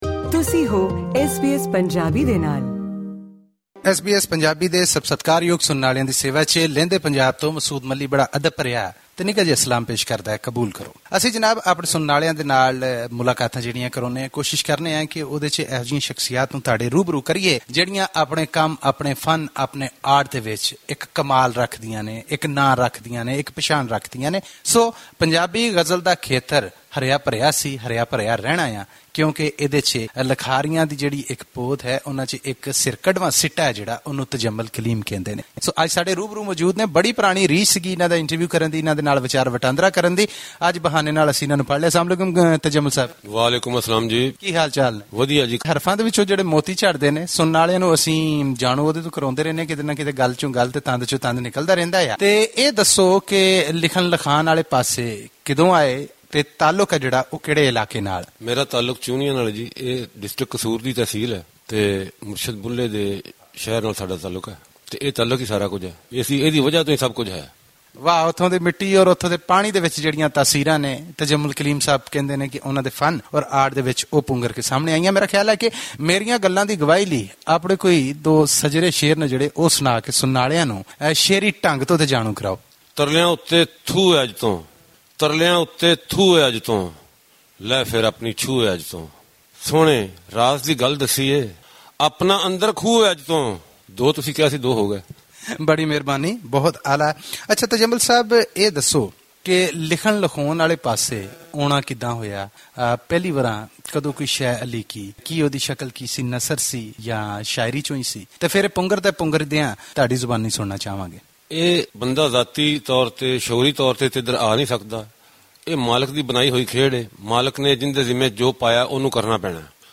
In his memory, SBS Punjabi presents an old interview with him in which he expressed his love for the Punjabi language.